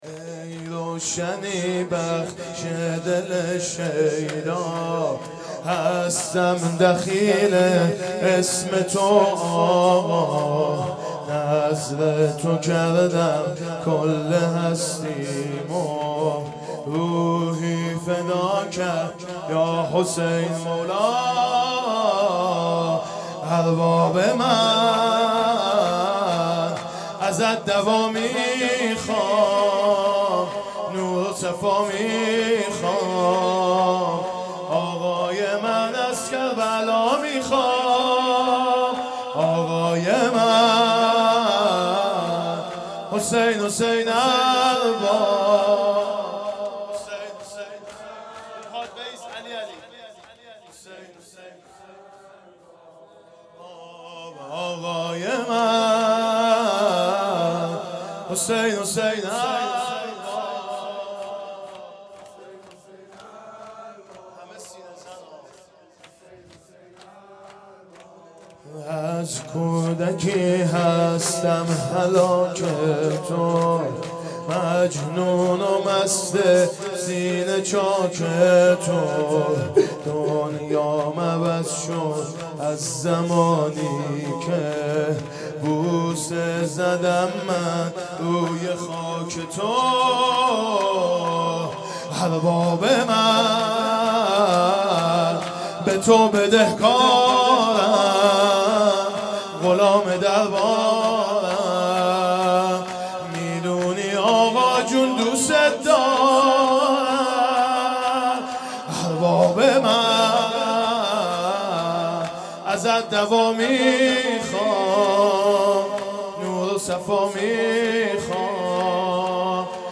شور
محرم 1440 _ شب دهم